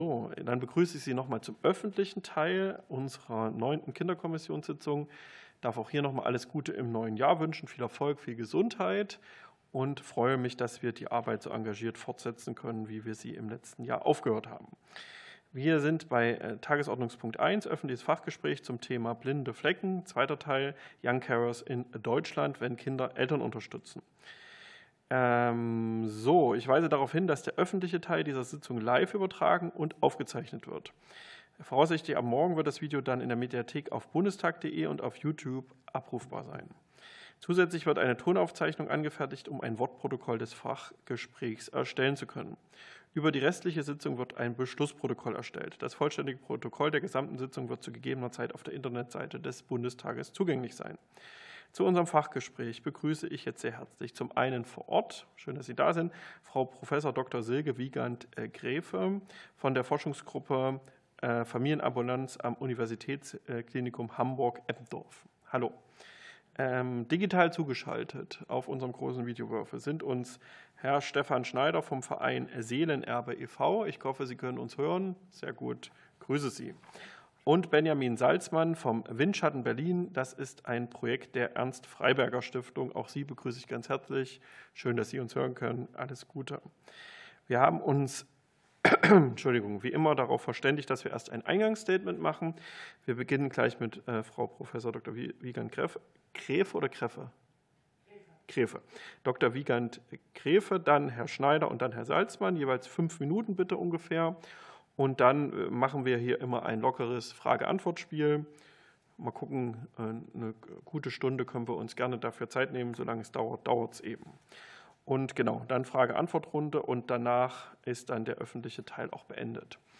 Fachgespräch der Kinderkommission